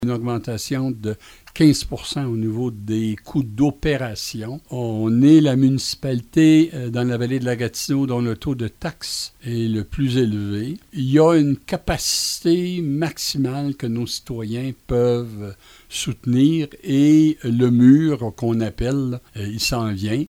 M. Guindon nous parle de la situation financière de Denholm :